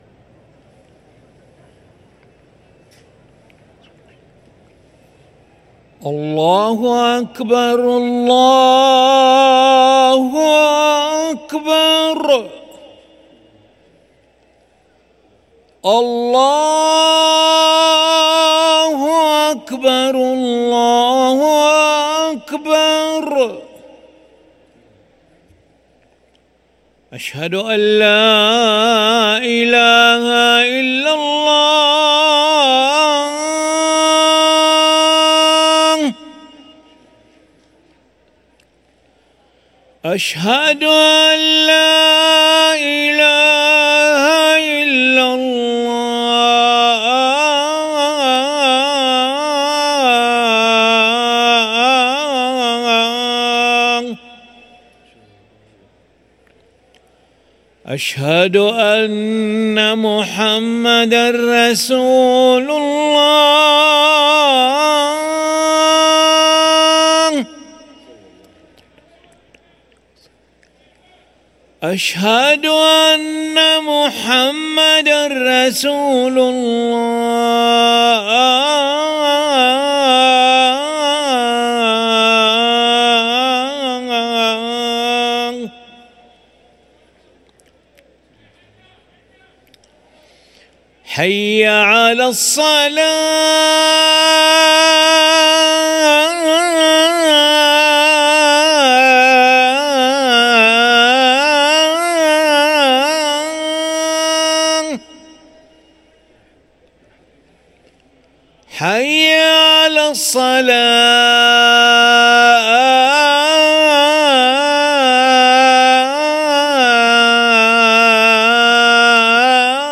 أذان العشاء للمؤذن علي ملا الأحد 18 رمضان 1444هـ > ١٤٤٤ 🕋 > ركن الأذان 🕋 > المزيد - تلاوات الحرمين